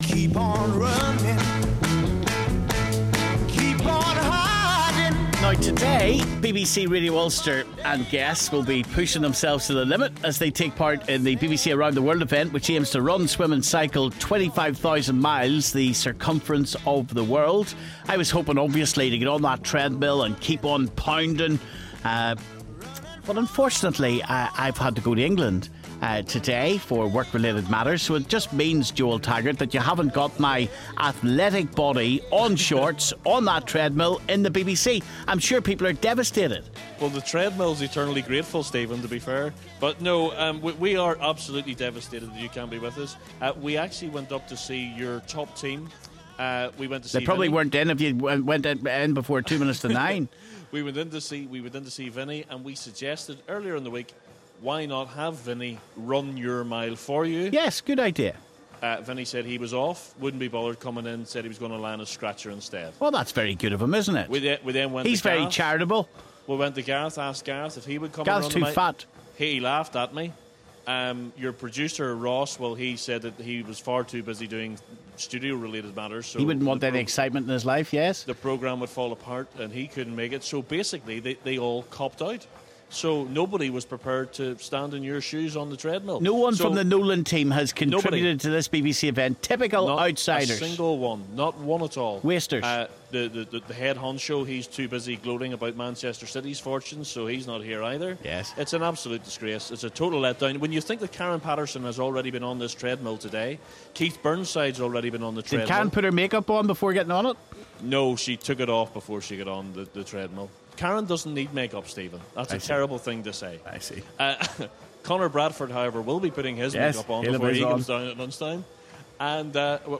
in the reception of Broadcasting House